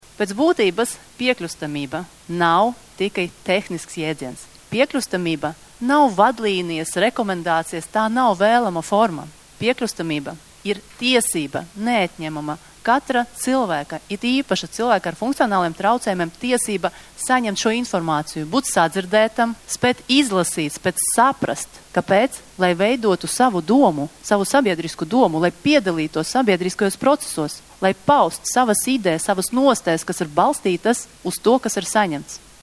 Atklājot pasākumu tiesībsaredze vērsa uzmanību, ka tiesības uz informāciju ir neatņemama cilvēktiesību sastāvdaļa.
Tiesībsardze Karina Palkova